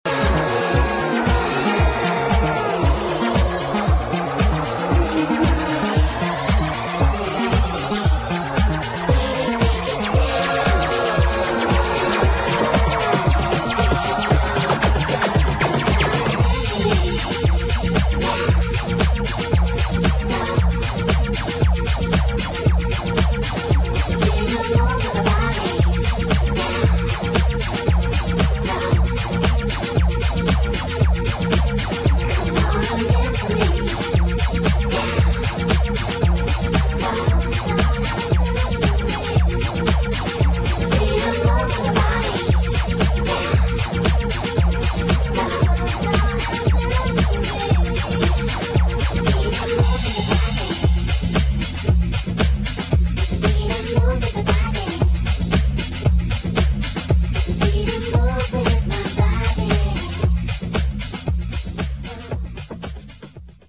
[ ELECTRO POP | HOUSE | BREAKS ]